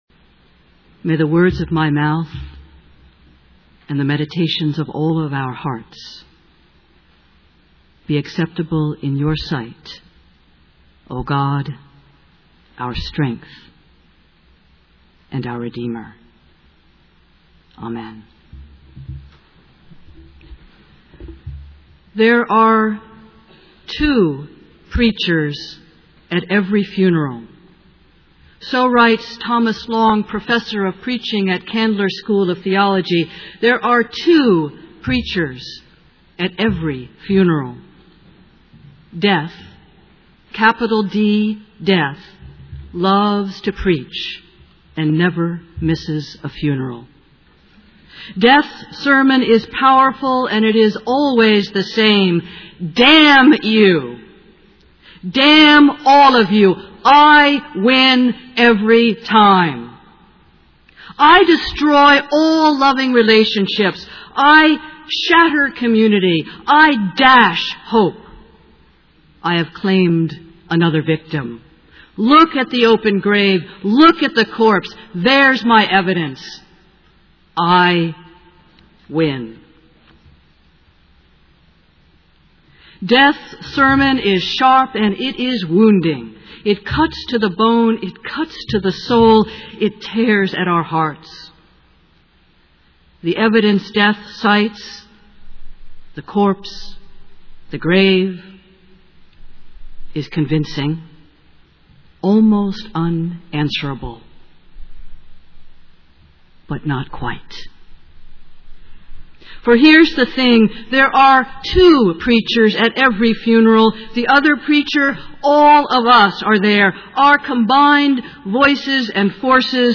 All Saints Day Reflection